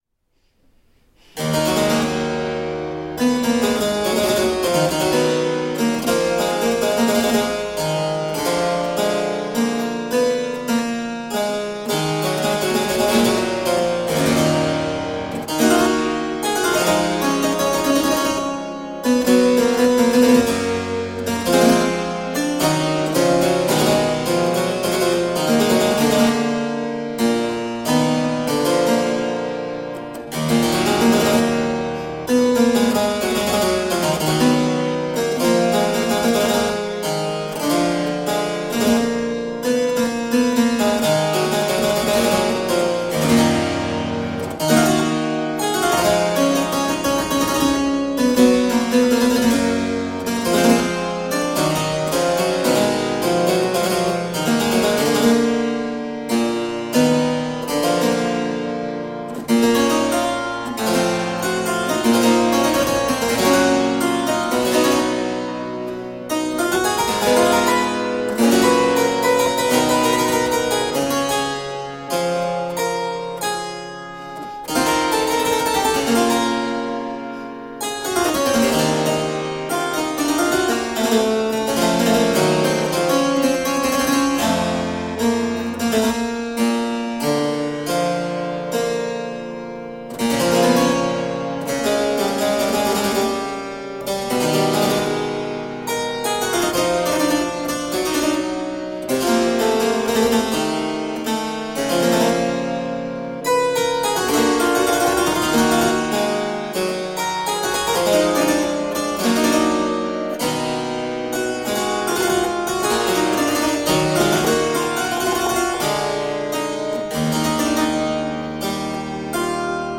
Deeply elegant harpsichord.
Classical, Baroque, Renaissance, Instrumental
Harpsichord